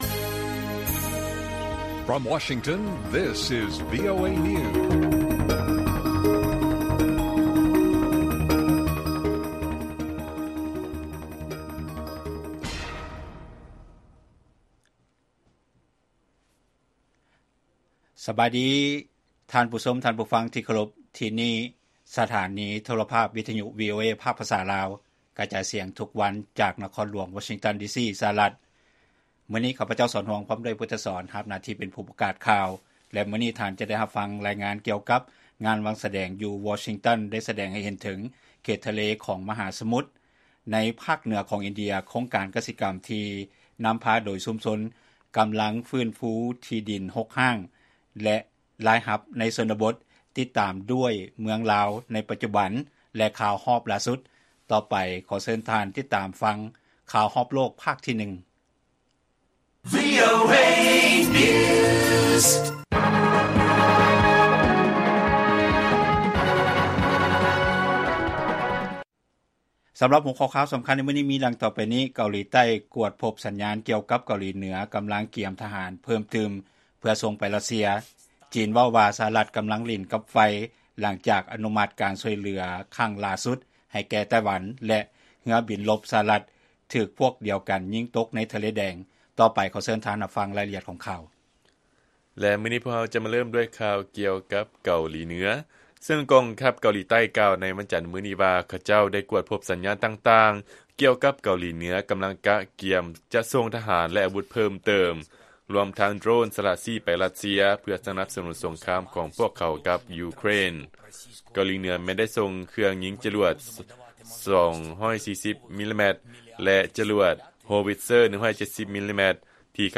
ລາຍການກະຈາຍສຽງຂອງວີໂອເອລາວ: ເກົາຫຼີໃຕ້ ກວດພົບສັນຍານ ກ່ຽວກັບ ເກົາຫຼີເໜືອ ກຳລັງກະກຽມທະຫານເພີ່ມເຕີມເພື່ອສົ່ງໄປ ຣັດເຊຍ